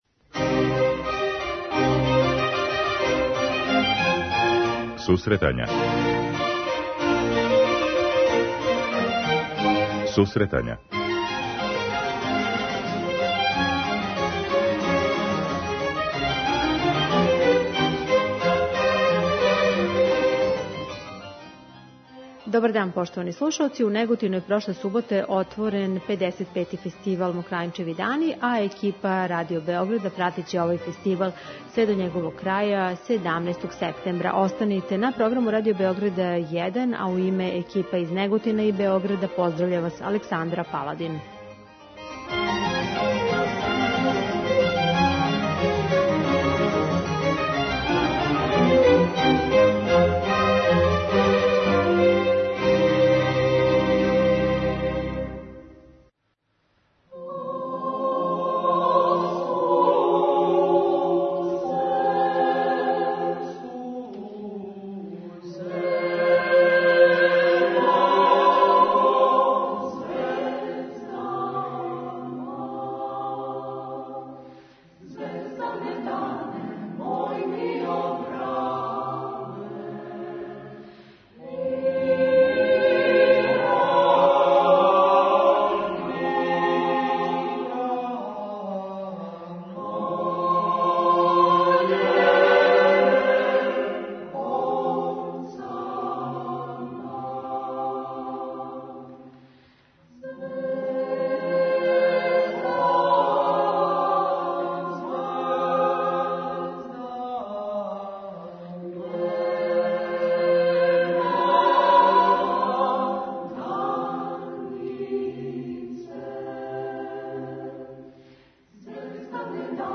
Један од најзначајнијих музичких фестивала у нашој земљи, 'Мокрањчеви дани', свечано је отворен у суботу, 11. септембра. У данашњој емисији представљамо део фестивалске атмосфере кроз разговоре са гостима и композиције, које су снимљене захваљујући Преносној техници Радио Београда, која са лица места снима концертне догађаје.